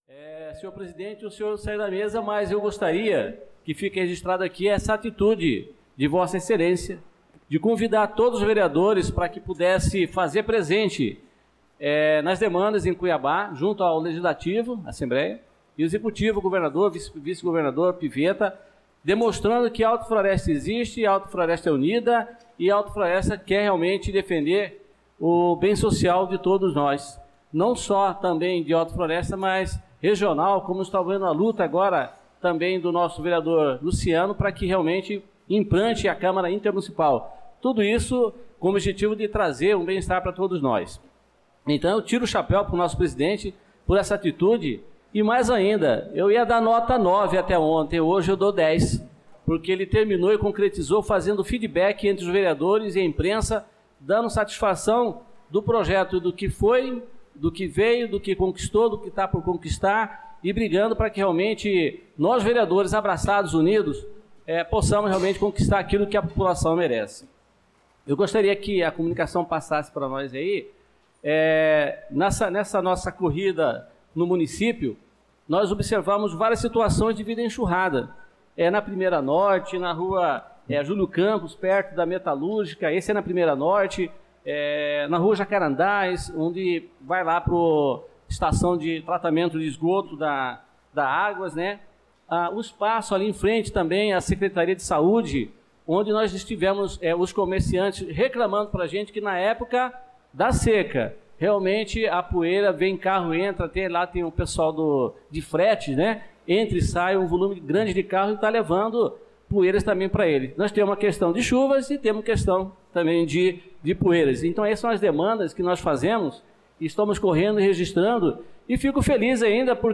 Pronunciamento do vereador Adelson Servidor na Sessão Ordinária do dia 18/02/2025